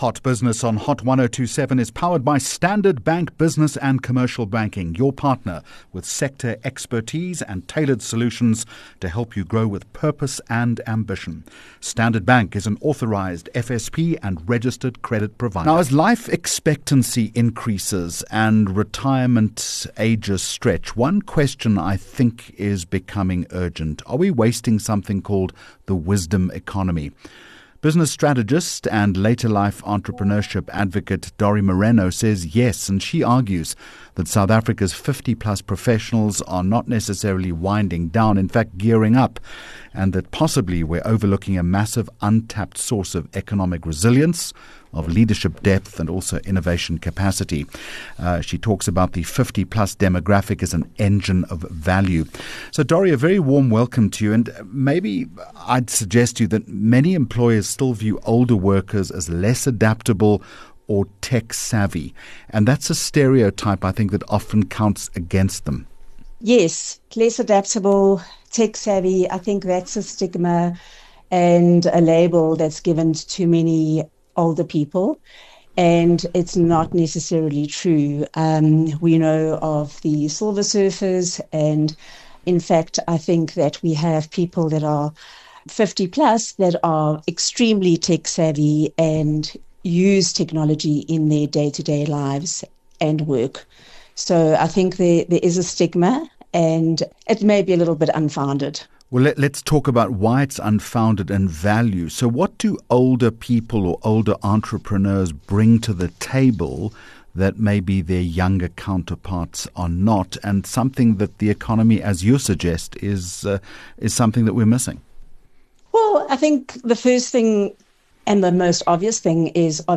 10 Jul Hot Business Interview